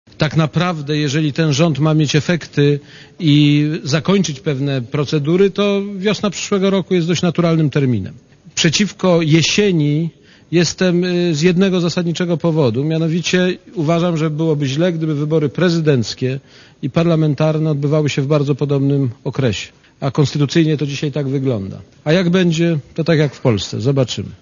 Myślę, że to dobra wiadomość dla nas wszystkich: Polska ma rząd, który dysponuje wotum zaufania - powiedział prezydent Aleksander Kwaśniewski na konferencji prasowej w Sejmie po tym, gdy rząd Marka Belki uzyskał wotum zaufania.
* Mówi prezydent Aleksander Kwaśniewski*